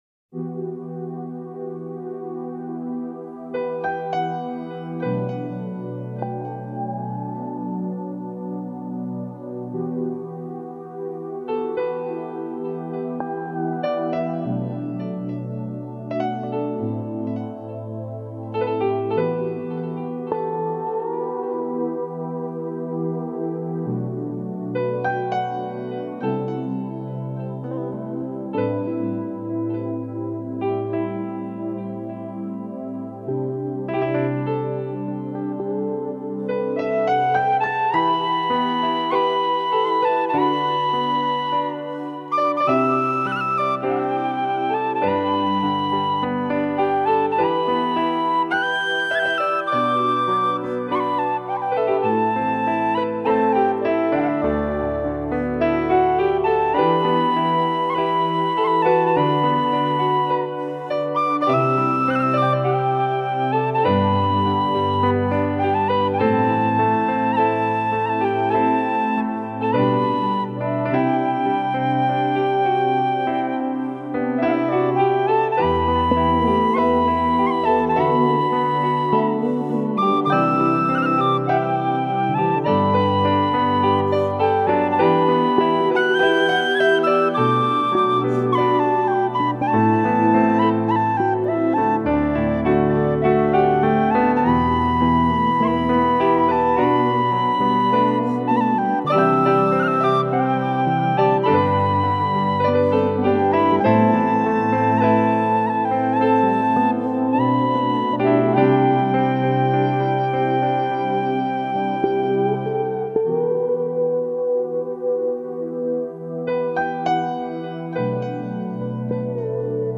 凯尔特音乐
典型的凯尔特风味，古老、源远流长，可以听到提琴、风笛、